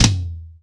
TOM     3B.wav